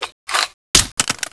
hmg_clipout.wav